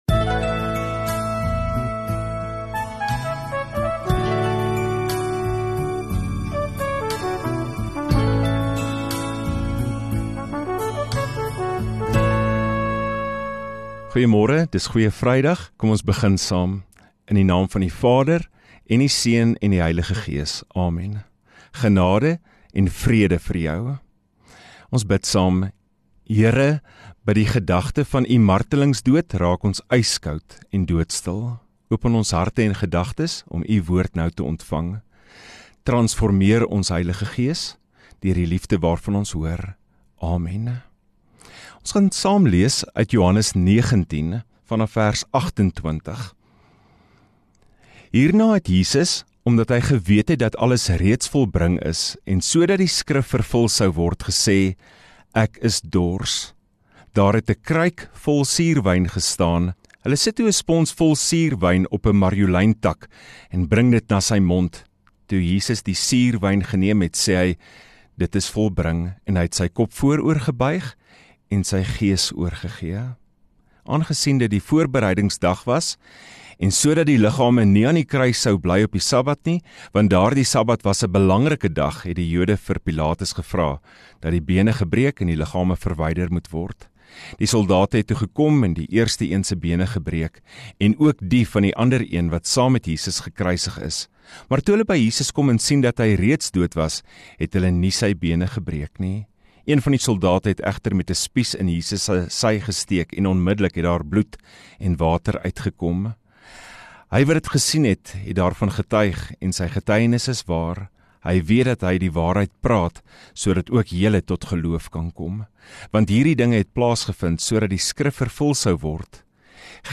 29 Mar Vrydagoggend Erediens